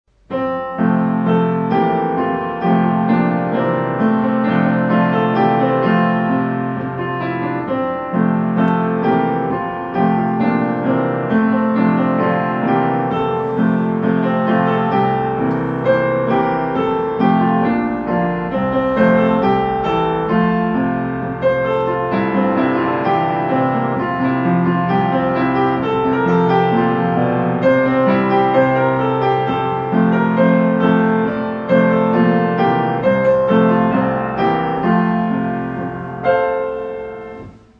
関根小校歌（伴奏のみ） (音声ファイル: 576.6KB)